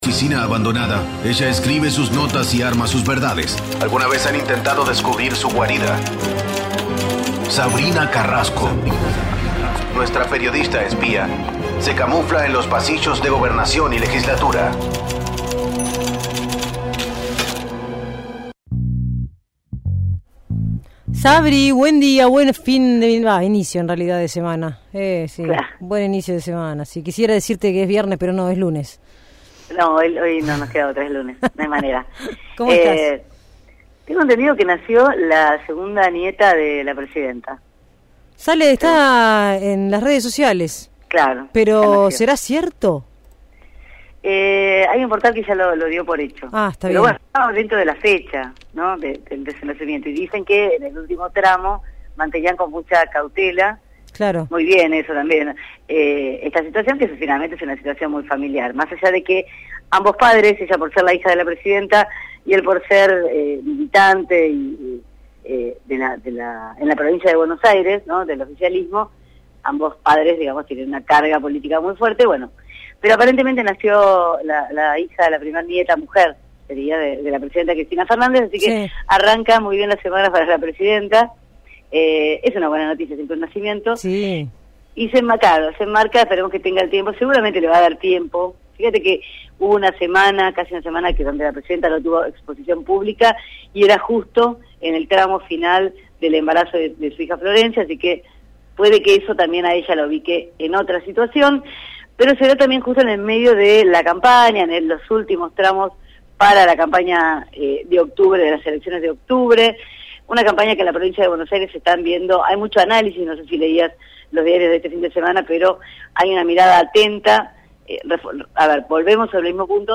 desde Gobernación, realizó un repaso político de lo sucedido el fin de semana, en especial en torno a la jornada electoral de ayer en Tucumán.